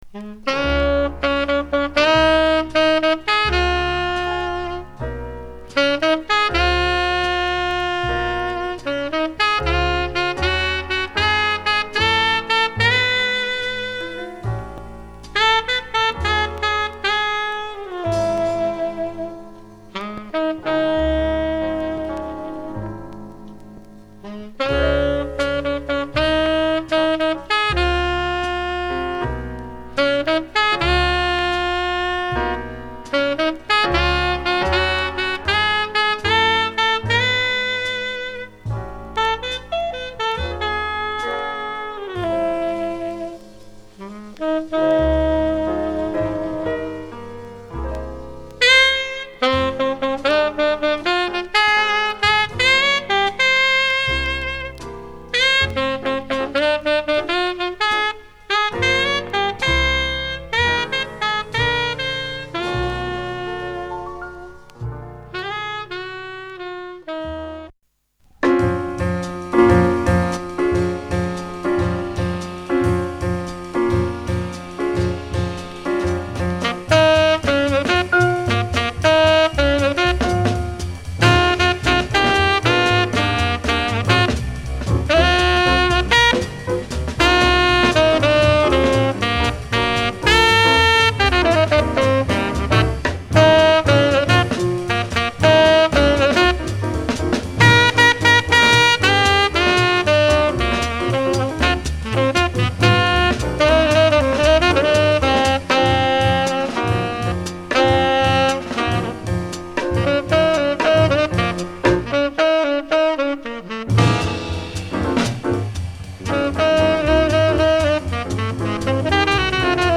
◆mono盤はモノラルMCカートリッジ、stereo盤はステレオMCカートリッジでの評価となります。
Press：US
Format：LP